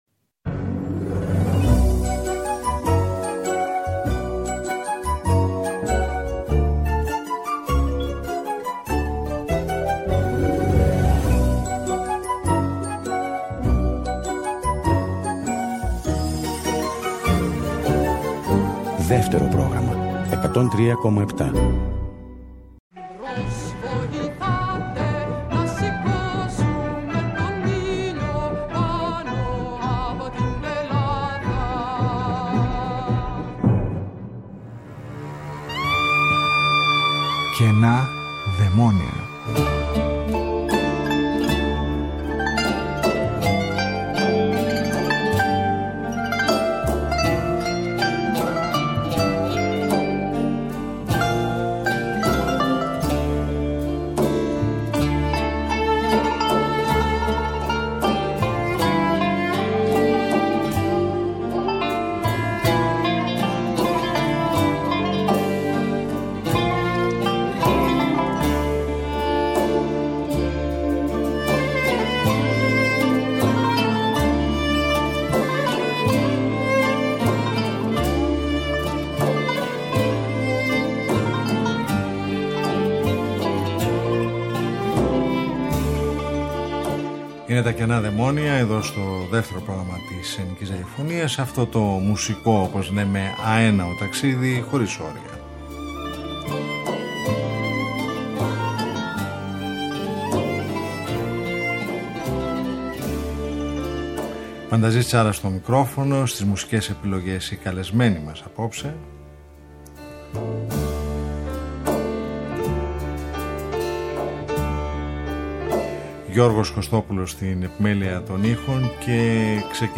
Το συγκρότημα δημιουργήθηκε το 2009 και η μουσική τους έχει επιρροές από την κλασική, τη τζαζ, το art rock, τη folk, την ambient, το dark wave, την avant-garde.
Ένα μουσικό ταξίδι με ηχοτόπια που … δεν σ’ αφήνουν ποτέ ασυγκίνητο